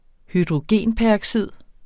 Udtale [ hydʁoˈgeˀnpæɐ̯ʌgˌsiðˀ ]